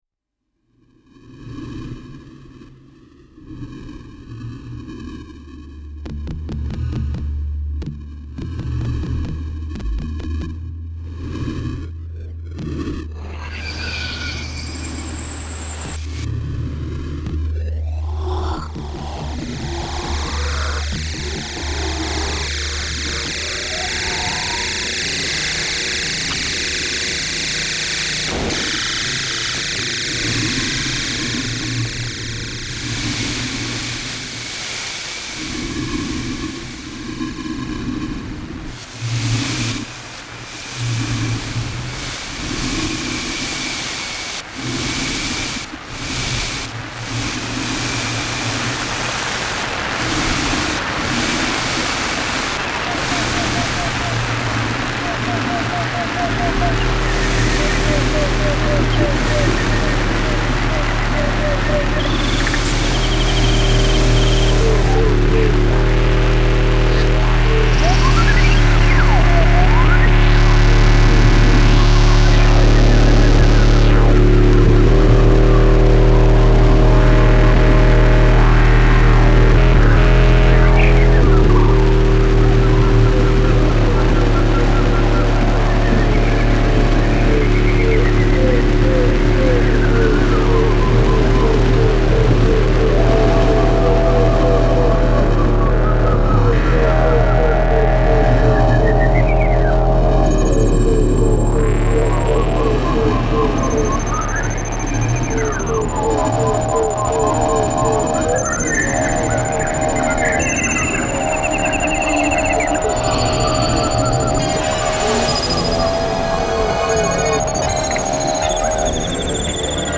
improvisations